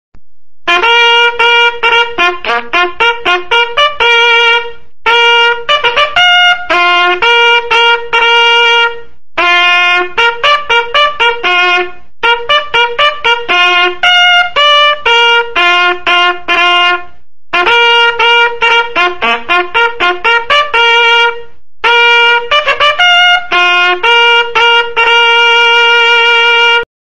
Toque De Corneta – Escola Tiradentes Cuiabá
20.Toque-do-Hasteamento-da-Bandeira-Nacional.mp3